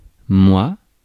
Ääntäminen
France (Paris): IPA: /ˈmwa/